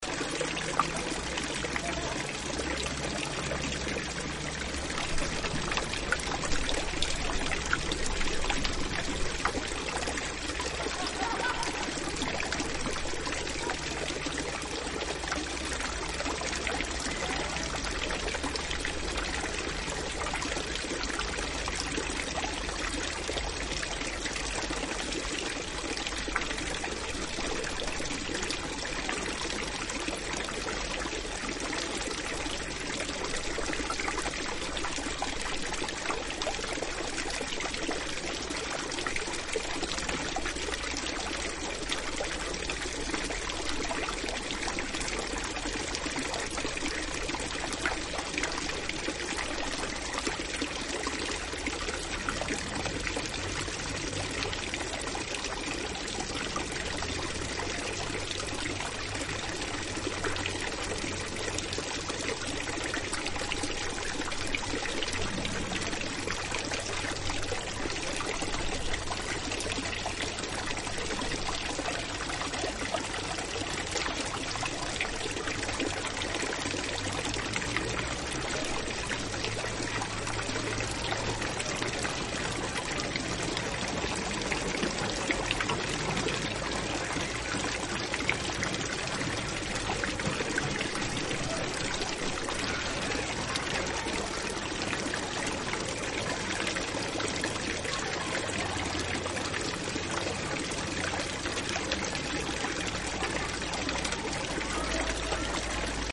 The sound of the water in the pilar del Toro
sound of the water , water
Sounds of the pilar del Toro